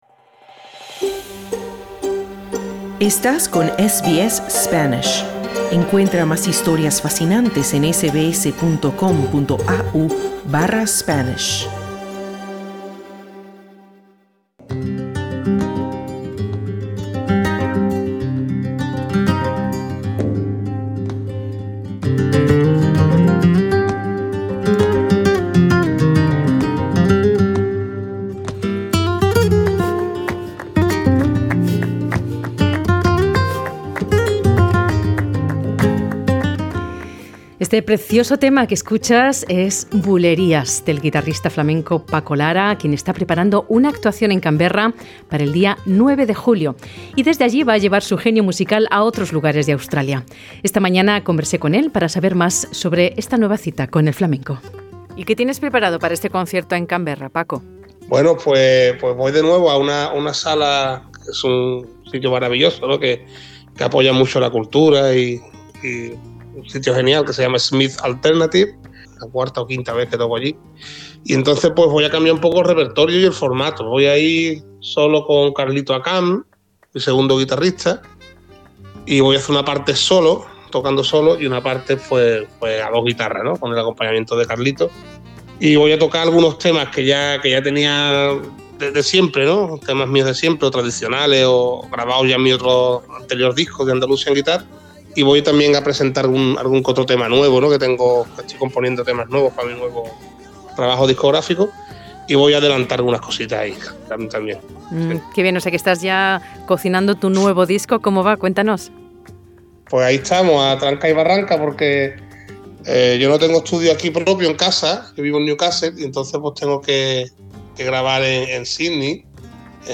Escucha la entrevista con SBS Spanish.